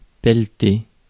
Ääntäminen
Synonyymit peller Ääntäminen France Tuntematon aksentti: IPA: /pɛl.te/ Haettu sana löytyi näillä lähdekielillä: ranska Käännös Ääninäyte Verbit 1. shovel US Määritelmät Verbit Remuer à l’aide d’une pelle .